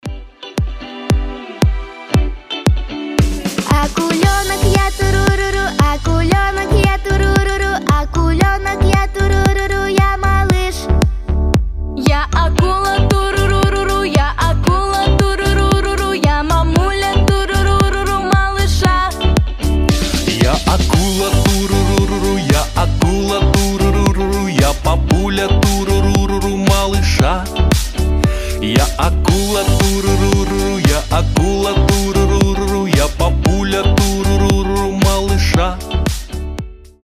забавные
милые
Cover
Детские песни